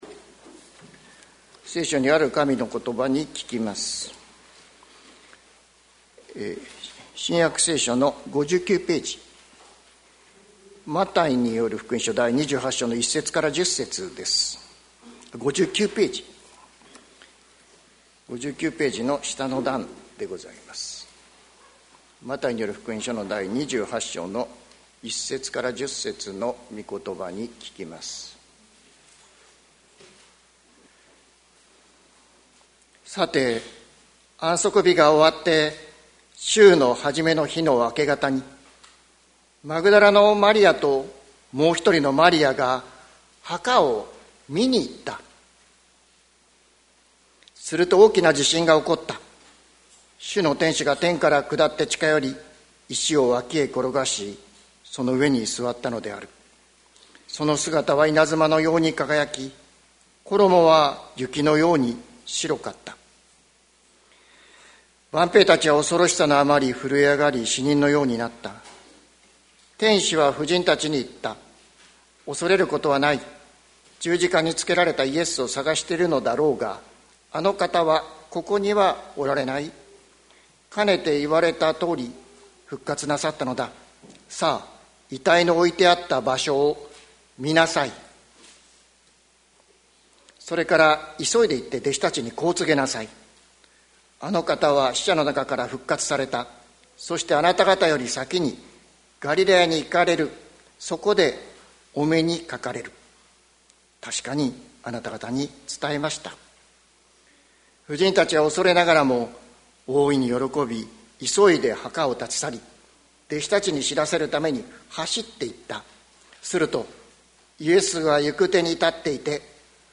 2025年04月20日朝の礼拝「ここからもう一度」関キリスト教会
説教アーカイブ。